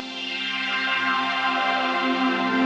SaS_MovingPad03_90-C.wav